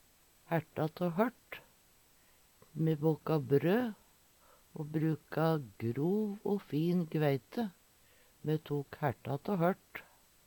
hærta tå hørt - Numedalsmål (en-US)